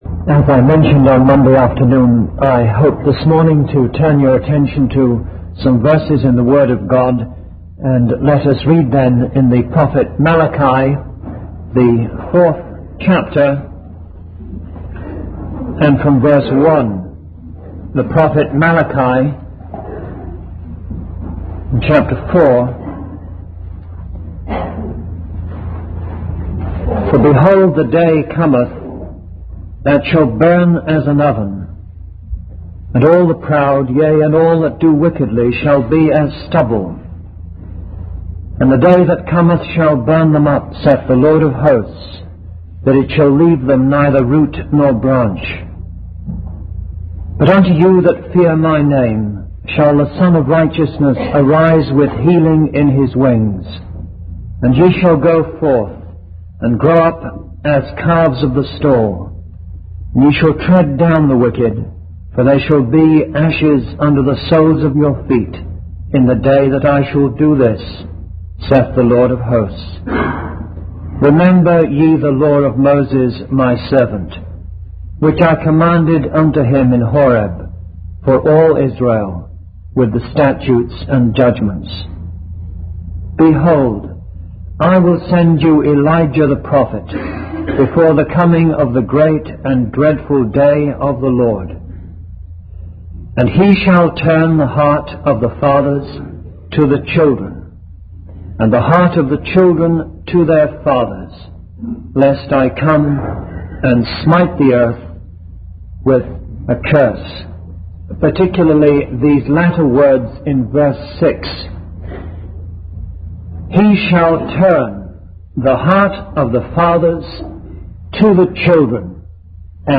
In this sermon, the preacher focuses on the verses from the book of Malachi, specifically chapter 4.